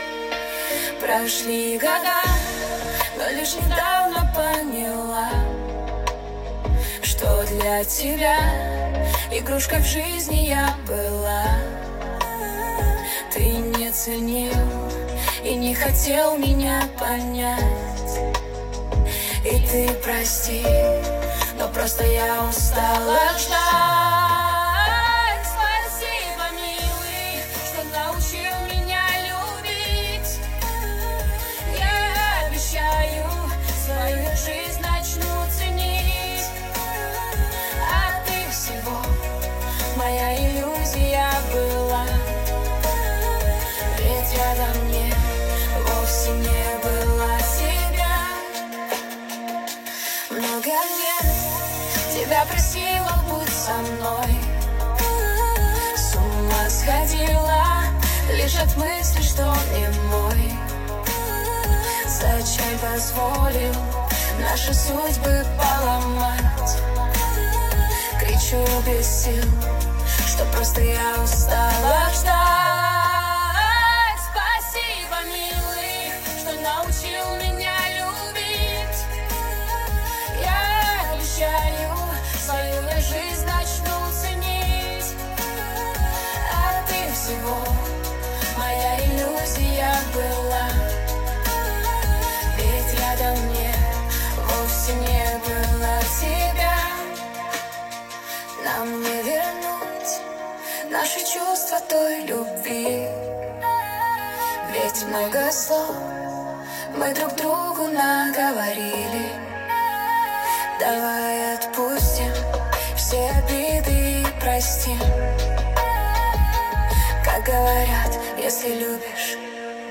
Качество: 320 kbps, stereo
АИ нейро песня